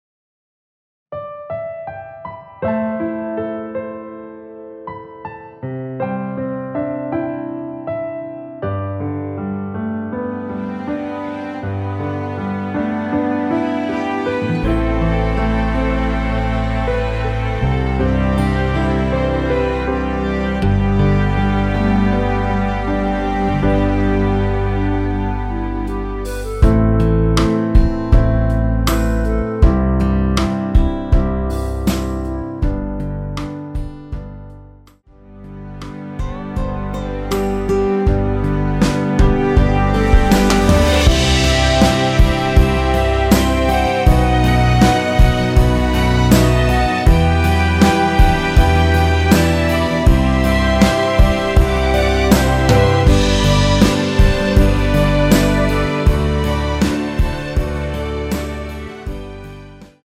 멜로디 MR입니다.
원키에서(+1)올린 멜로디 포함된 MR입니다.
앞부분30초, 뒷부분30초씩 편집해서 올려 드리고 있습니다.
중간에 음이 끈어지고 다시 나오는 이유는